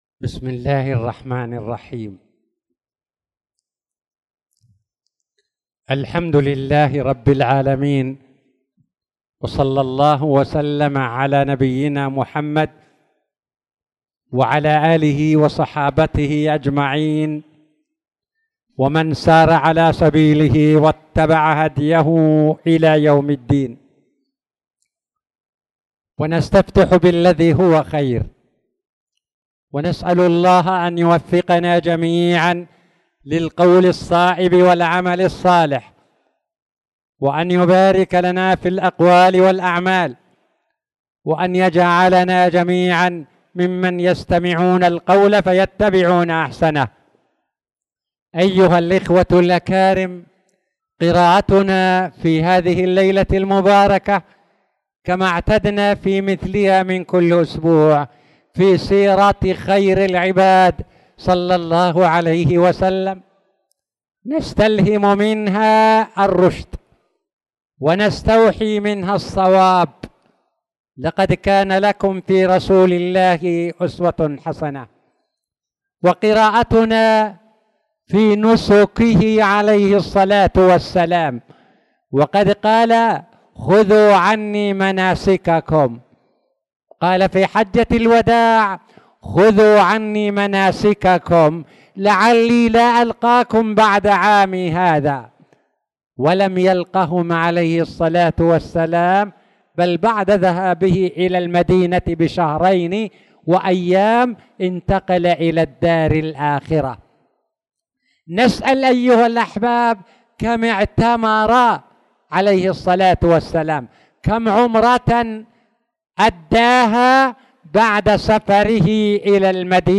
تاريخ النشر ٢٣ شوال ١٤٣٧ هـ المكان: المسجد الحرام الشيخ